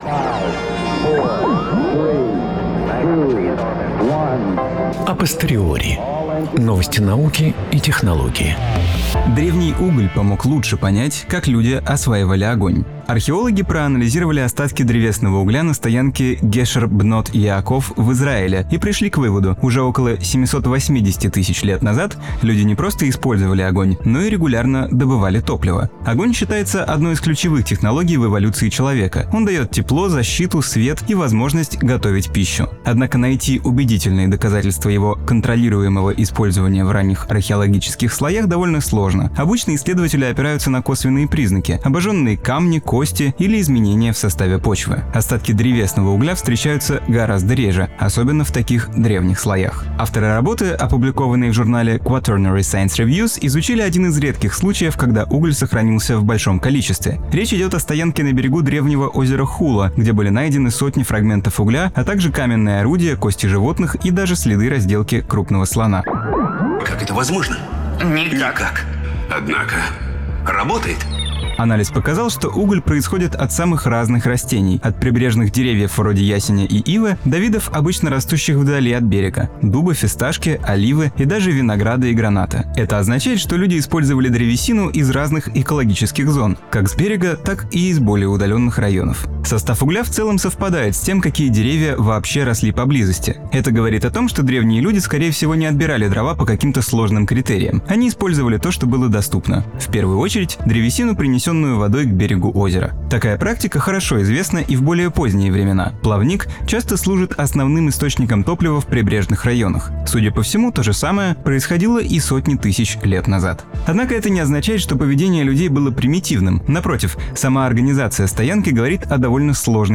Новости науки и технологий